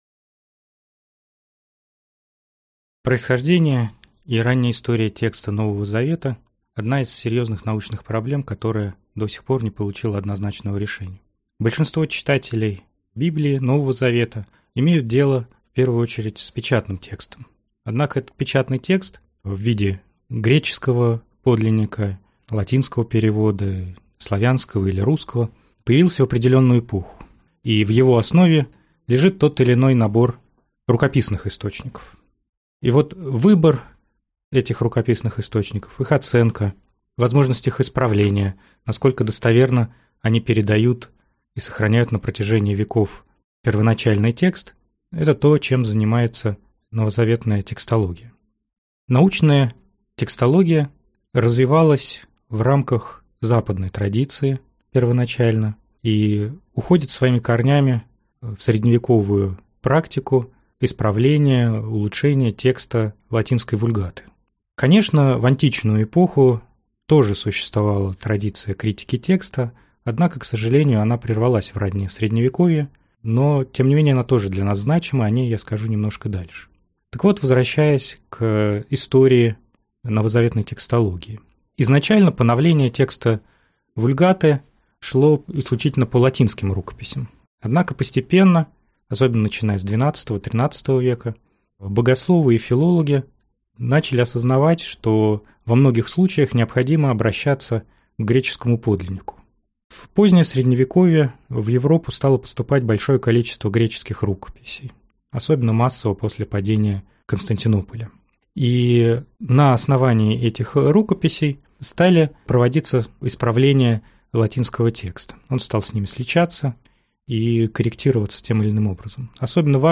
Аудиокнига Новозаветная текстология | Библиотека аудиокниг